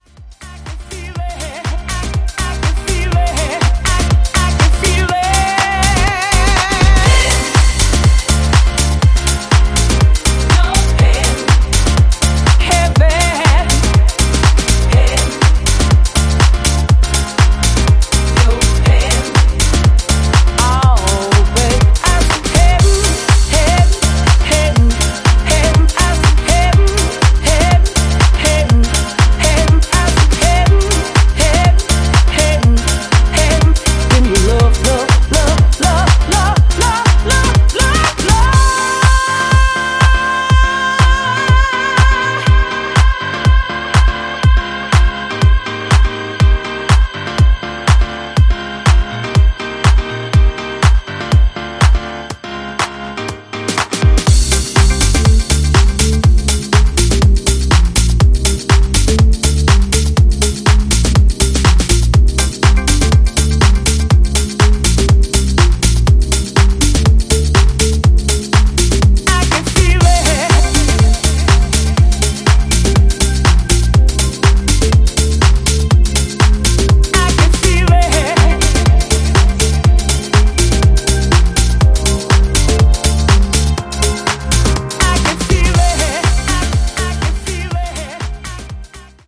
vocal-led, groove-driven house music